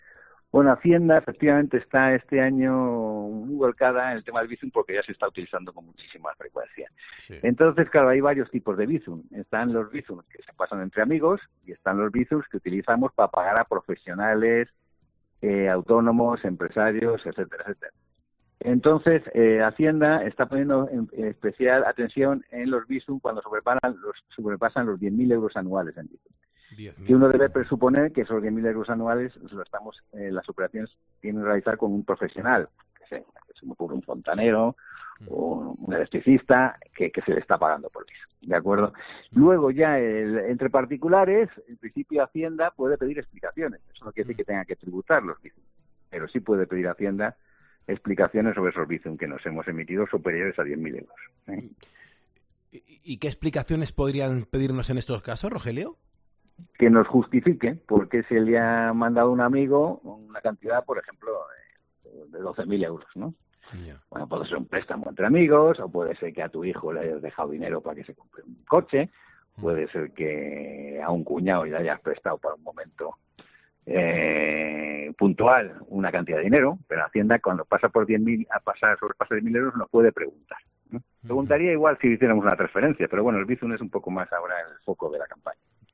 Sobre ello ha hablado un asesor fiscal en Poniendo las Calles.